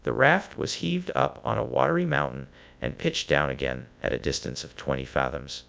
Text-to-Speech